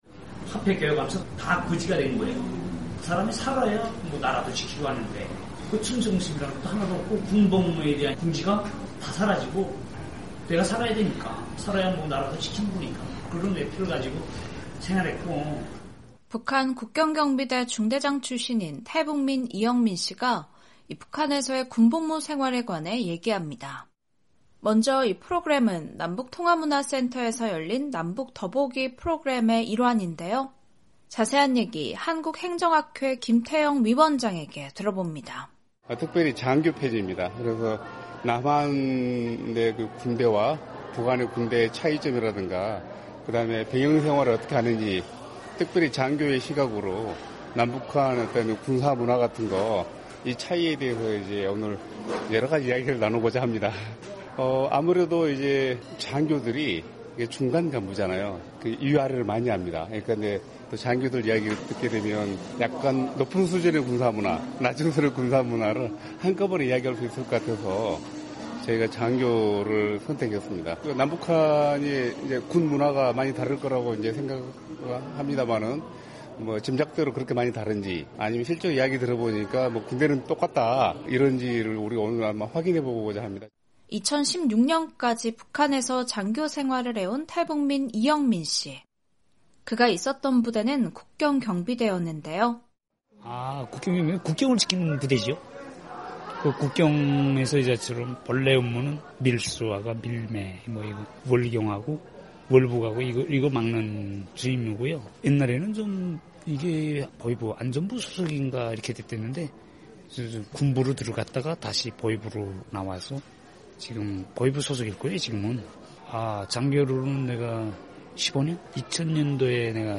최근 남북한 출신 군인들의 이야기를 들어보는 사회통합 토크콘서트가 열렸습니다.
남북한 출신 군인들의 같은 듯 다른 점에 관해 살펴보는 시간이 됐습니다. 탈북민들의 다양한 삶의 이야기를 전해드리는 '탈북민의 세상 보기', 오늘은 '남북더보기 군인 편' 현장으로 안내해 드립니다.